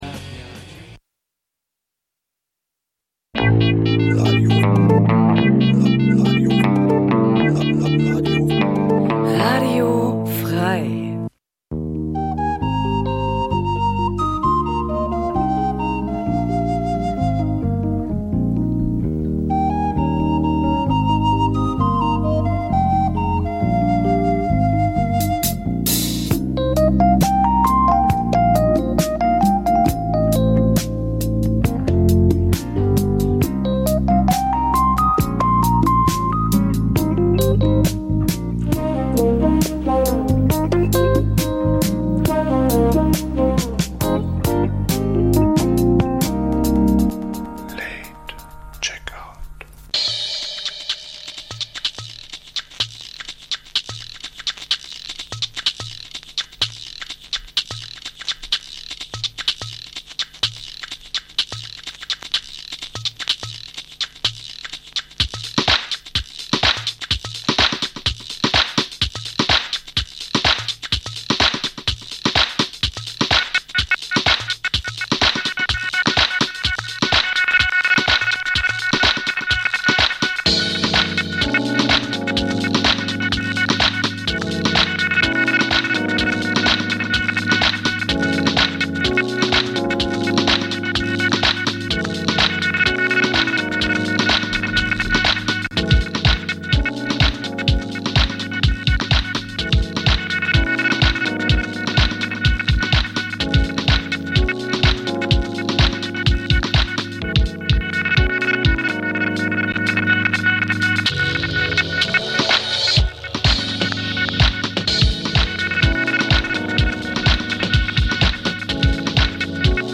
In der Sendung werden neben Neuerscheinungen aus der weiten Welt der Housemusik vor allem die Tracks jener Musikrichtung vorgestellt, die in den vergangenen Jahren nur kaum oder wenig Beachtung fanden. Au�erdem pr�sentieren wir dem H�rer unsere aktuellen Ausgeh-Tipps f�r das Wochenende und einen kurzen DJ-Mix.